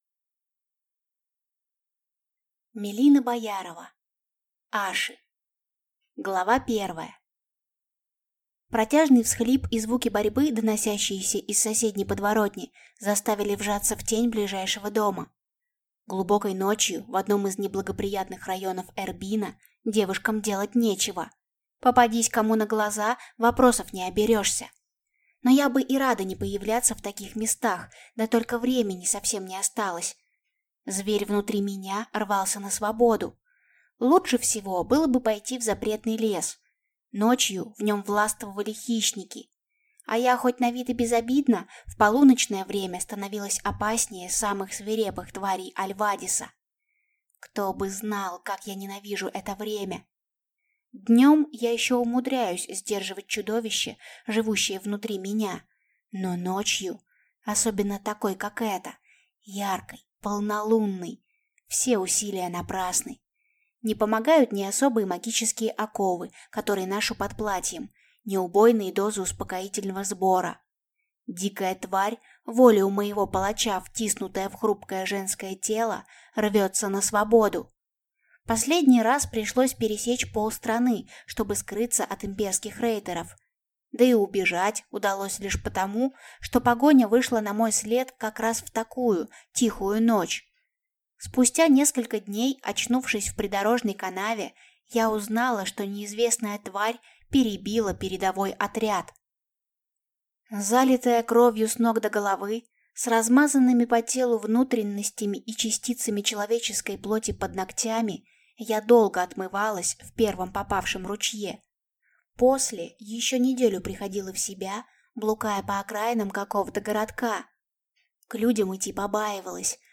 Аудиокнига Аши | Библиотека аудиокниг